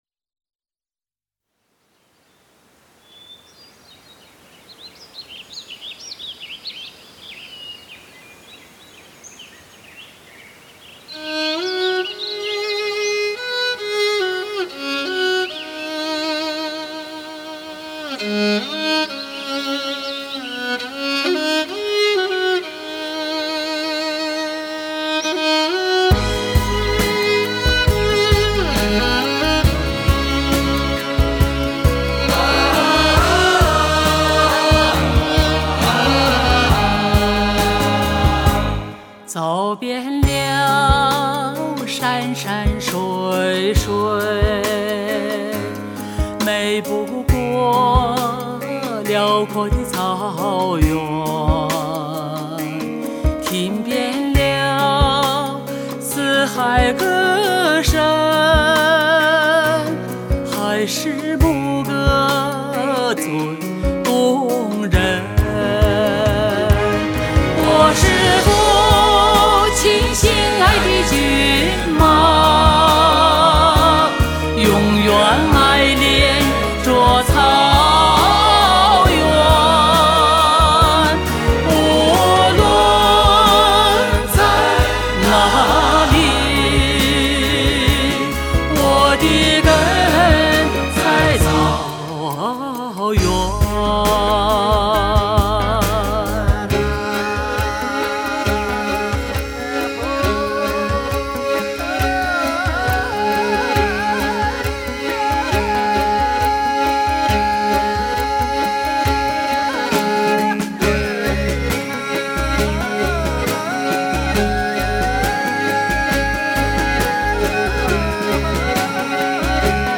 是一张用国际化的音乐语言诠释蒙古族民歌的专辑。